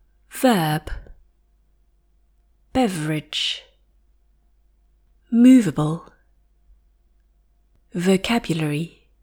In Spanish, the letters b and v are not usually contrastive, which can cause confusion when native Spanish speakers encounter English words like love or seven, where the “v” letter is pronounced with the top teeth on the bottom lip among British speakers.